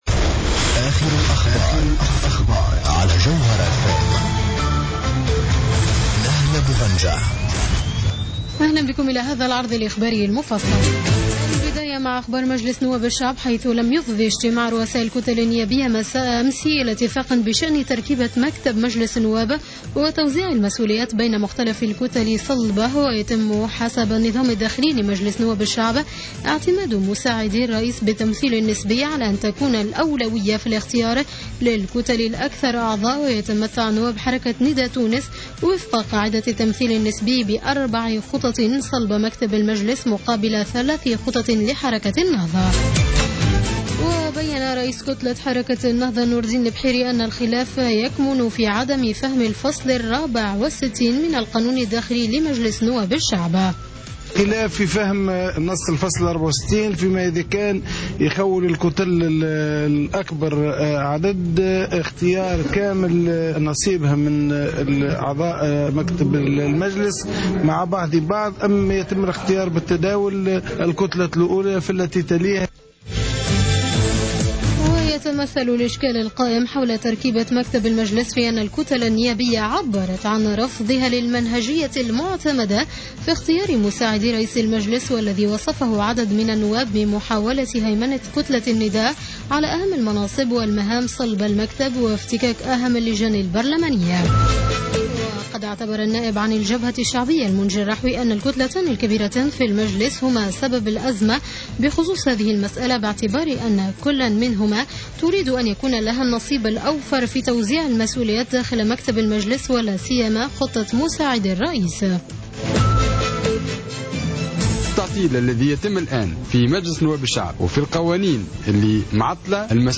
نشرة أخبار منتصف الليل ليوم السبت 14 فيفري 2015